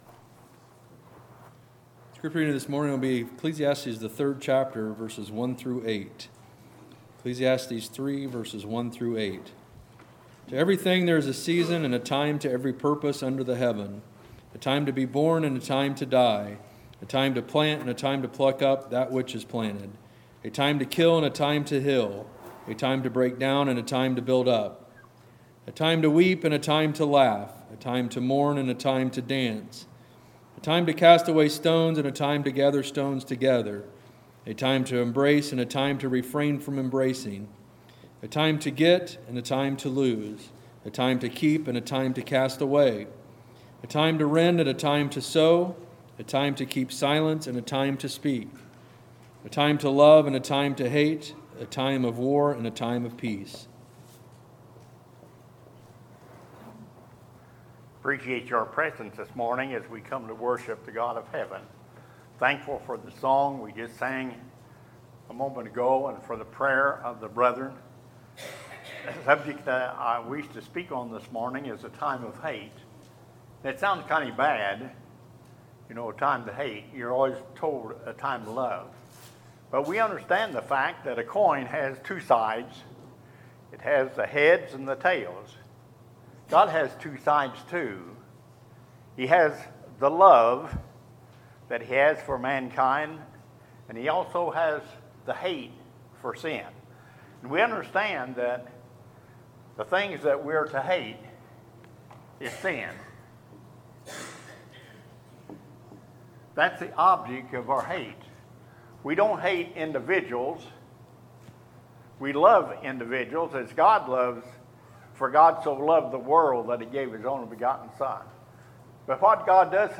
Sermons, June 23, 2019